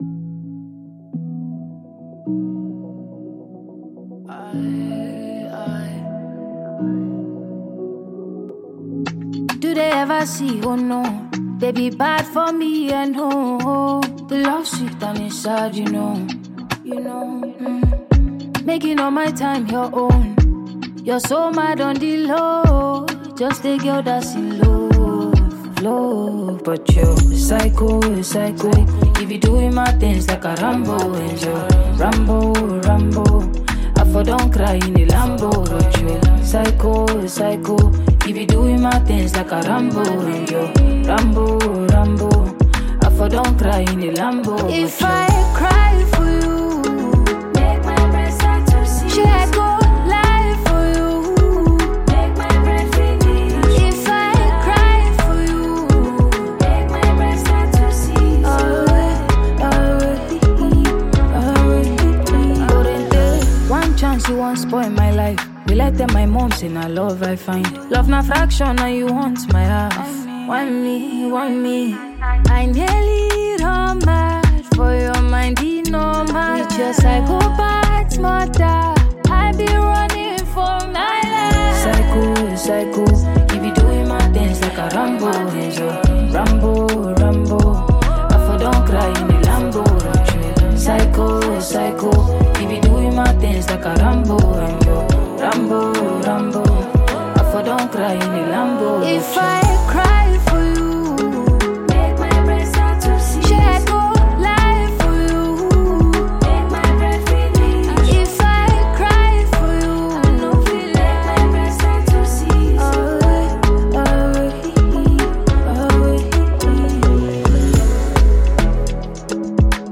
seductive song